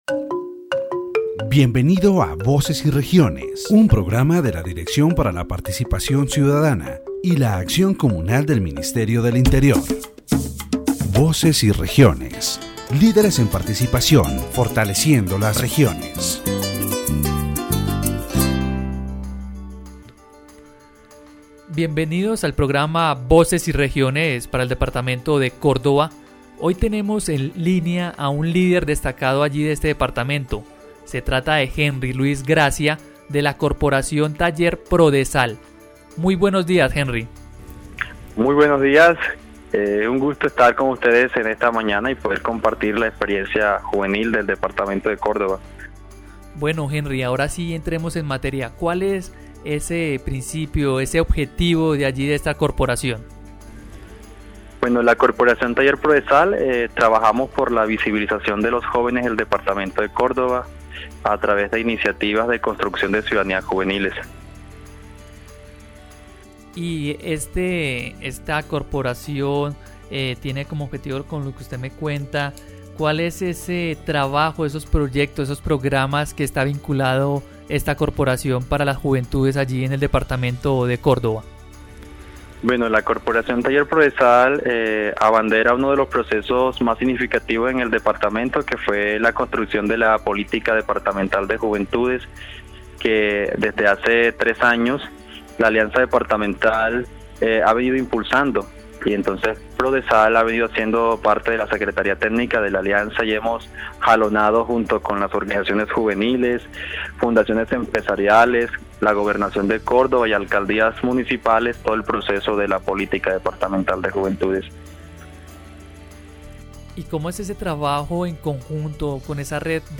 In this section of Voces y Regiones, the interviewee discusses forced displacement and the impact of armed conflict in the department of Córdoba, particularly in Montería. He mentions how violence has forced many people to flee their homes, referencing historical events such as the Banana Massacre and other conflicts that have affected the region.